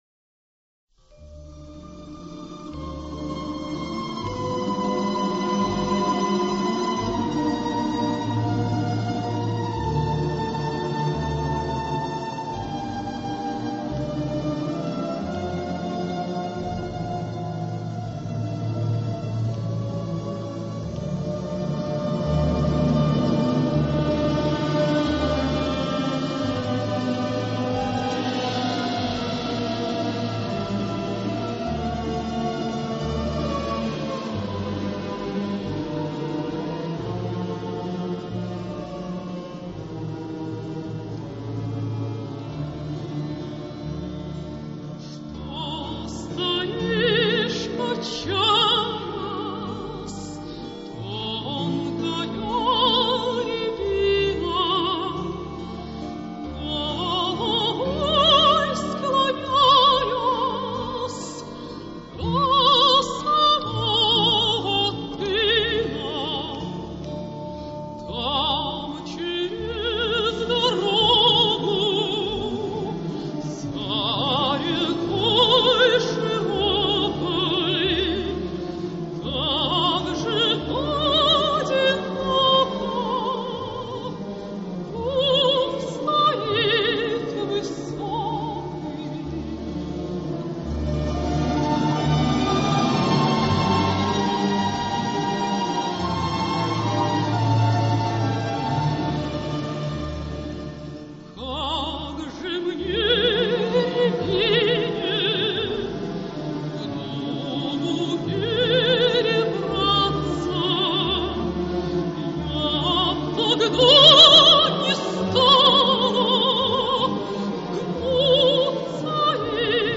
[ Русская народная песня ] 128kbps